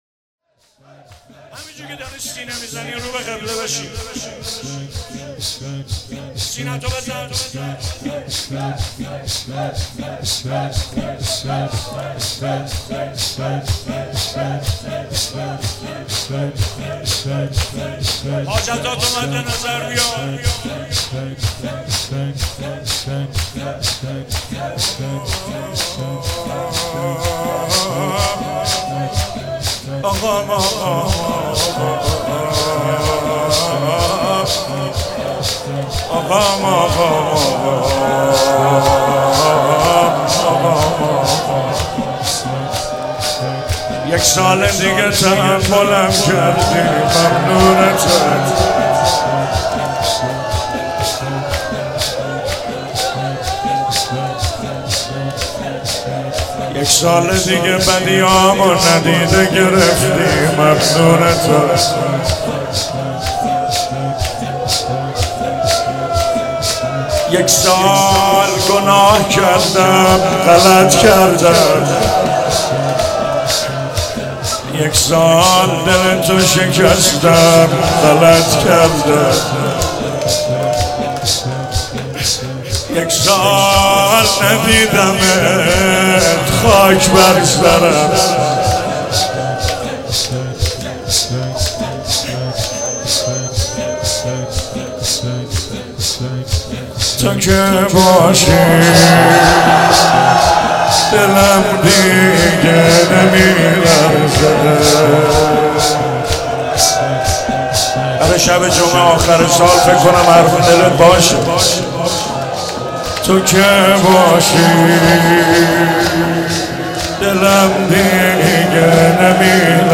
مراسم هفتگی 26 اسفند 95
چهاراه شهید شیرودی حسینیه حضرت زینب (سلام الله علیها)
شور